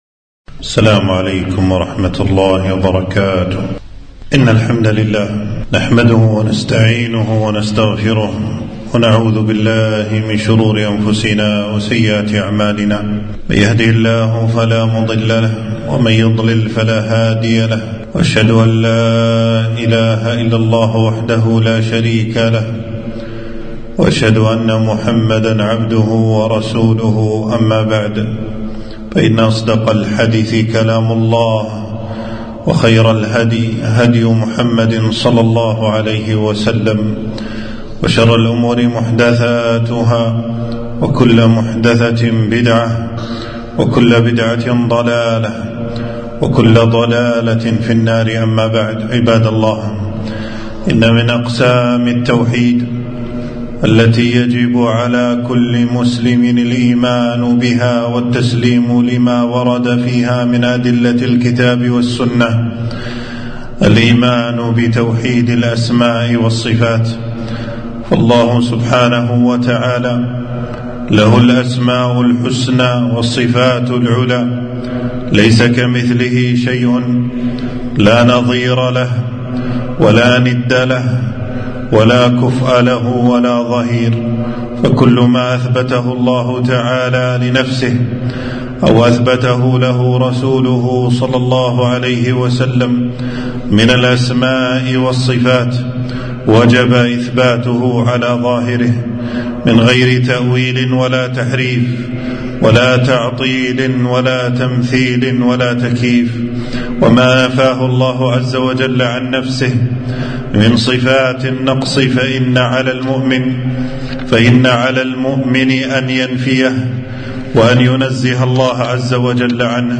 خطبة - إثبات المؤمنين لصفات رب العالمين ومنه العلو لله تعالى